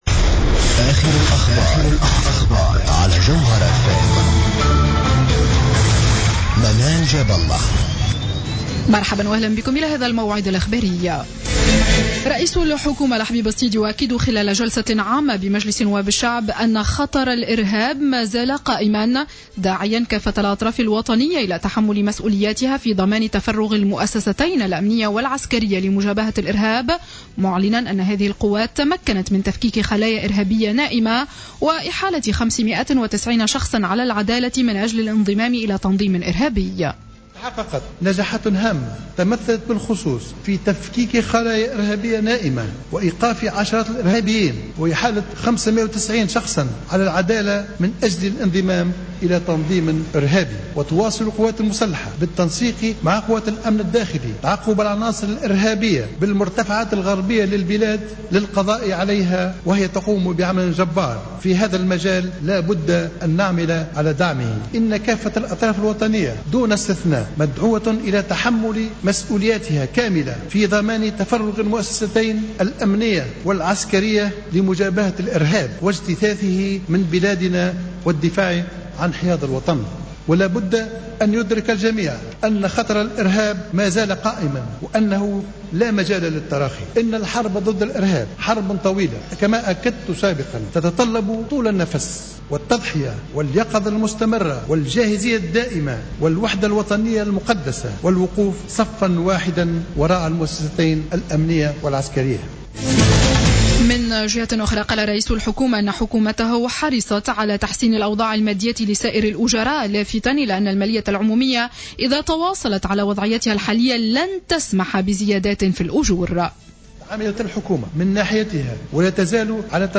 نشرة أخبار السابعة مساء ليوم الجمعة 05 جوان 2015